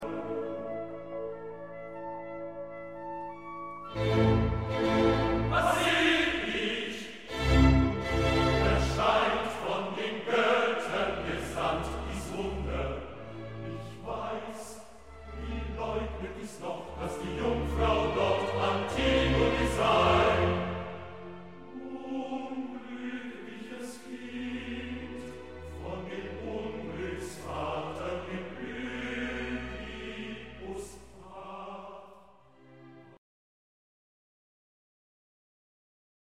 Il suo tentativo di ricreare soprattutto i ritmi dell'anapesto, sia pure in modo non scolastico o schematico, sono stati rilevati con interesse: valga il seguente esempio, tratto dal recitativo posto alla fine del N° 2, e riferito al momento in cui il Coro annuncia l'arrivo in scena di Antigone, sorpresa dalla guardia nel suo tentativo di dare sepoltura al fratello (vv. 376 ss.)